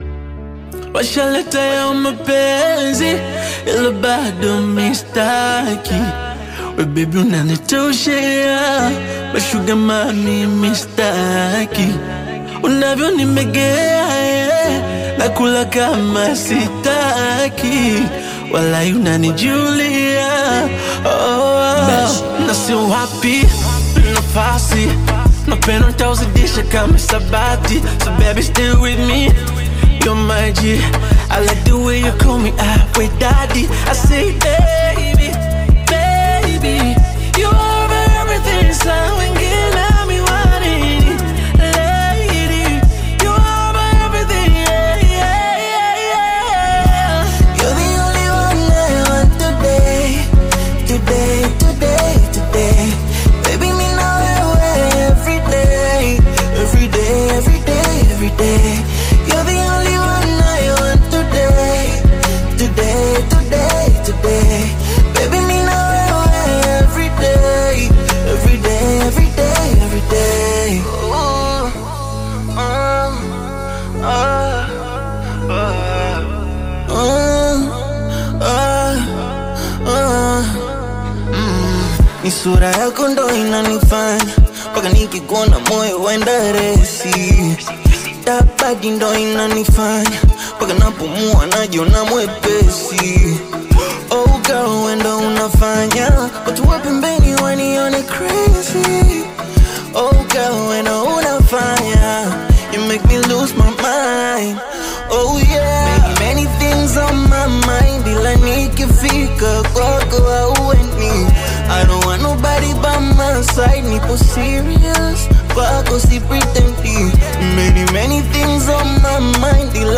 Bongo Flava Love Song